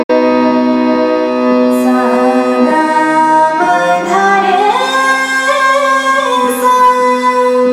Raga
ThaatPurvi
ArohaS G M d r’ S’
Basant (Aroha)